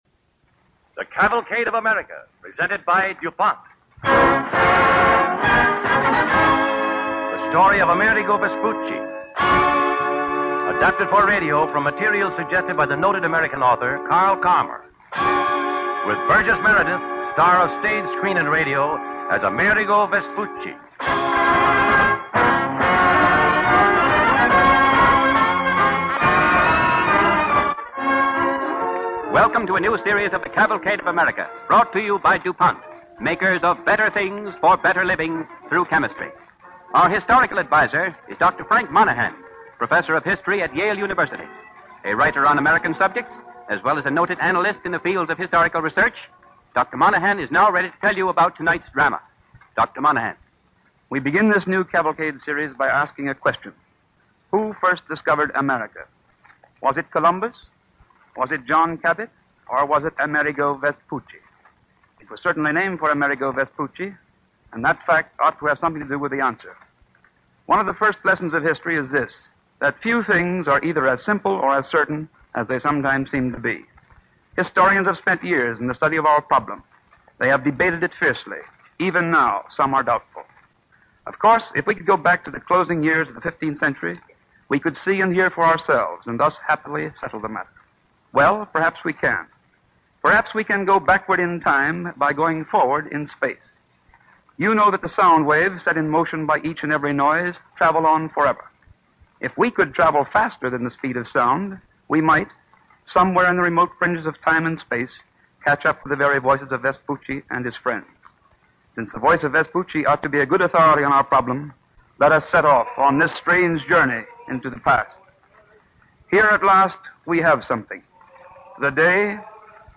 Amerigo Vespucci, starring Burgess Meredith